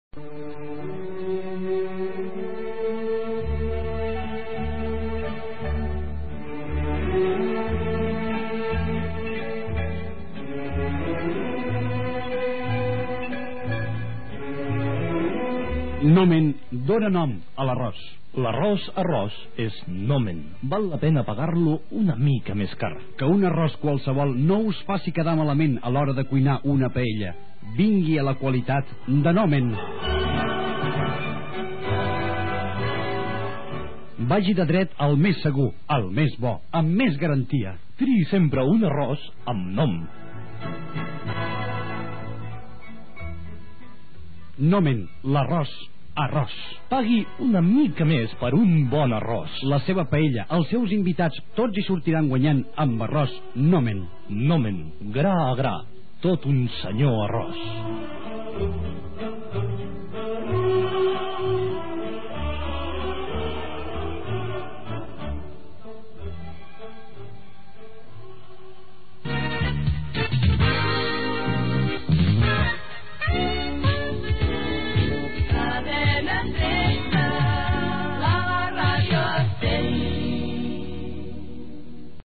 Publicitat, indicatiu de la cadena i del programa "La ràdio a cent"